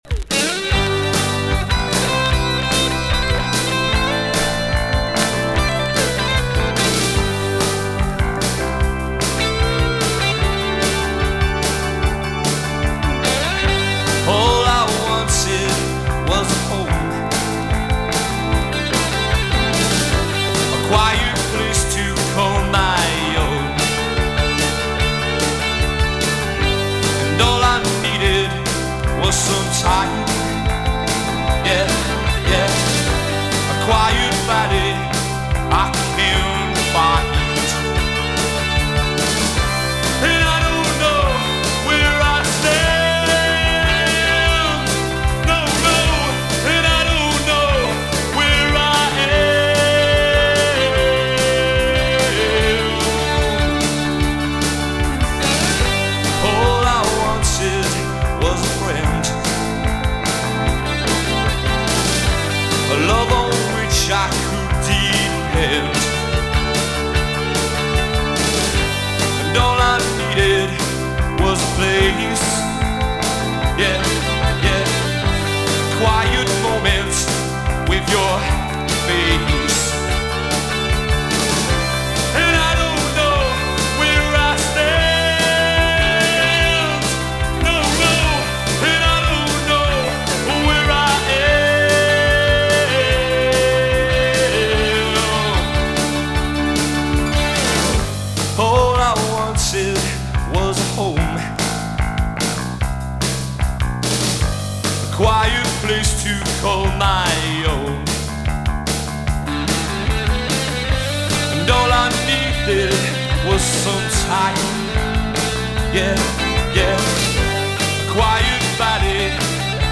vocals
guitar
keyboards
bass
drums